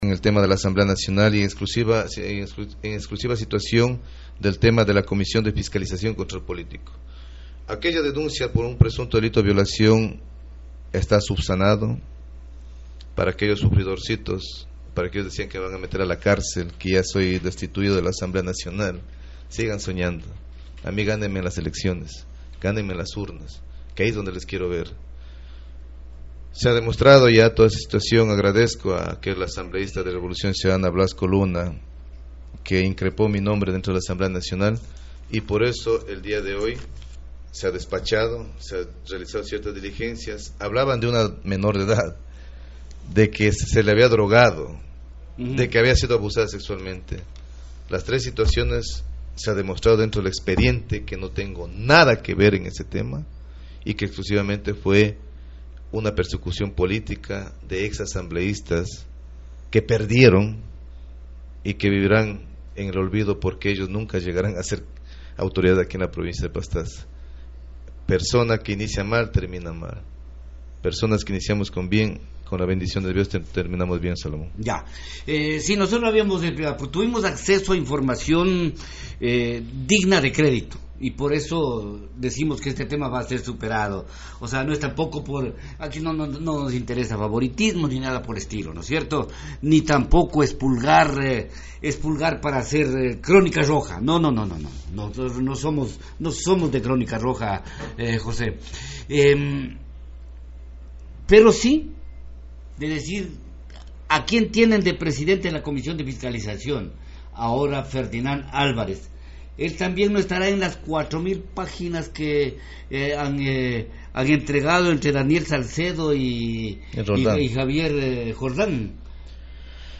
Entrevista: José Nanngo, asambleísta de Pastaza.